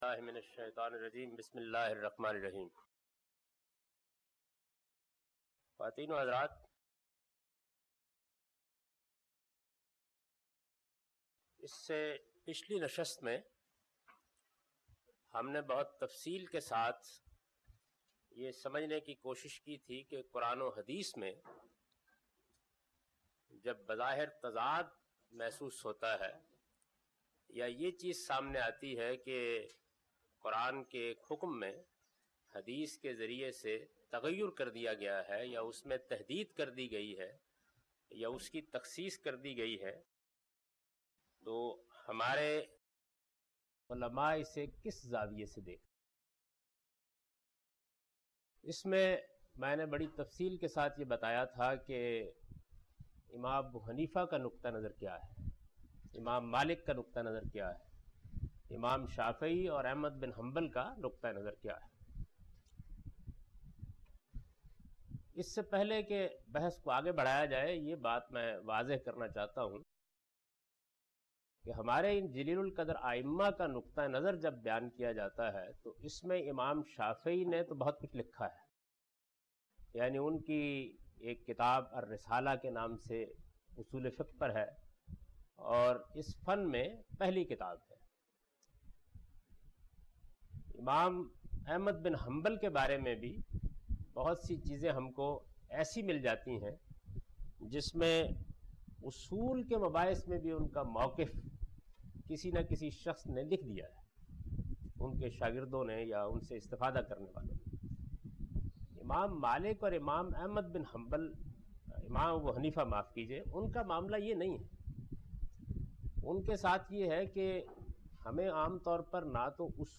A comprehensive course on Islam, wherein Javed Ahmad Ghamidi teaches his book ‘Meezan’.
In this lecture he teaches the the ruling of Hadith in order to interpret and understand the Quran. (Lecture no.33 – Recorded on 10th May 2002)